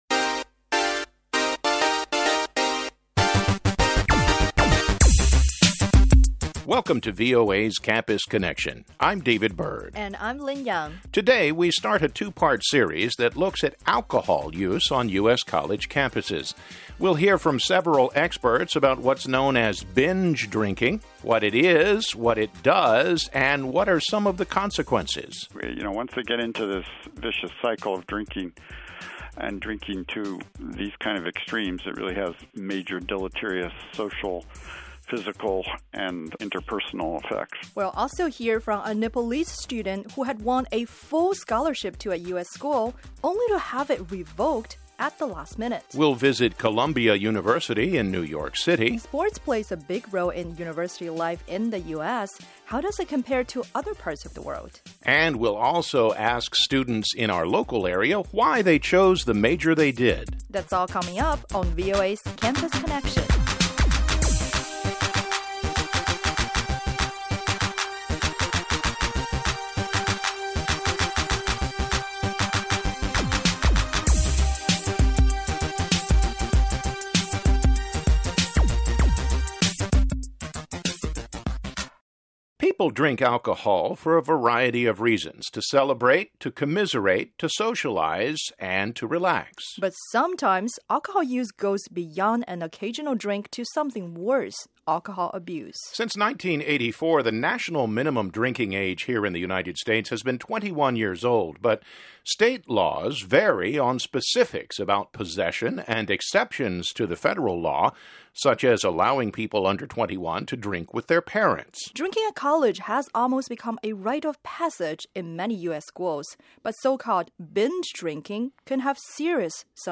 In this episode, we talk to a panel of experts about alcohol use and abuse on U.S. college campuses.